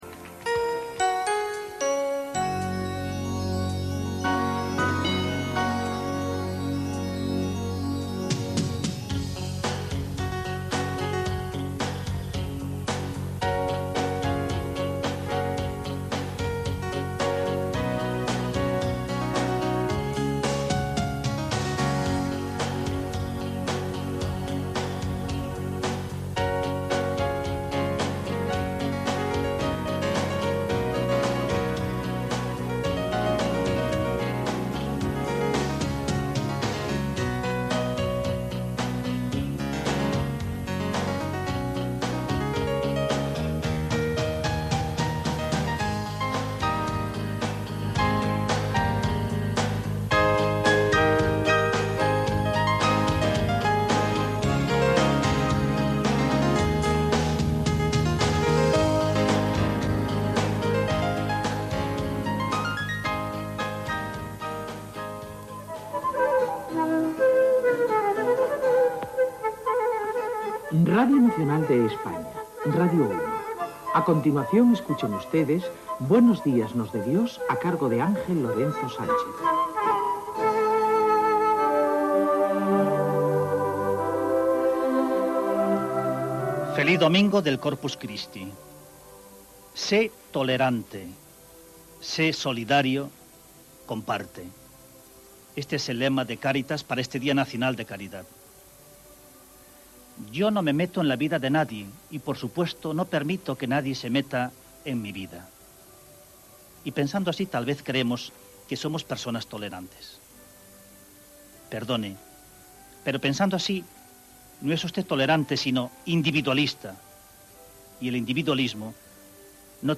Indicatiu musical de l'emissora, careta d'entrada del programa editorial sobre el significat del lema de Caritas per al Dia Nacional de la Caritat, careta de sortida.
Religió